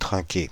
Ääntäminen
France (Île-de-France): IPA: /tʁɛ̃.ke/ Paris: IPA: [tʁɛ̃.ke]